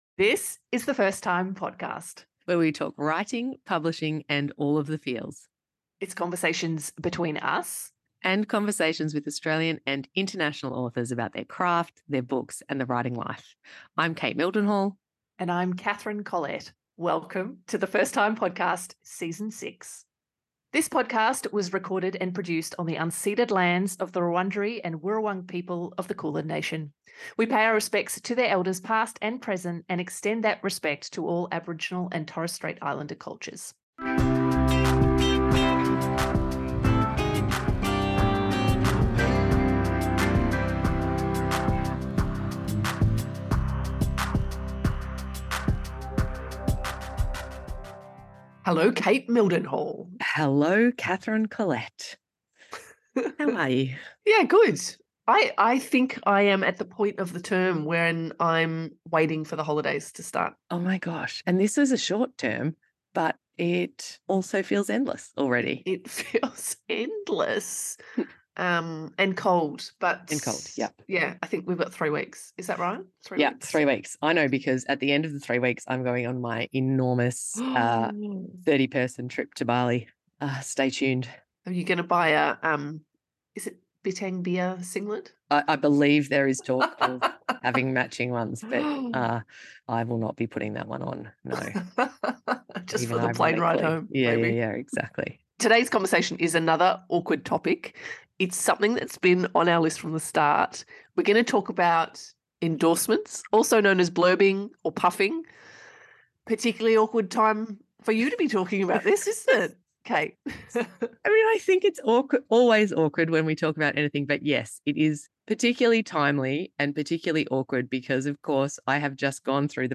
Today's ep is another awkward convo - one we've been looking forward to for awhile! We talk about endorsements - aka praise, puffs and blurbs.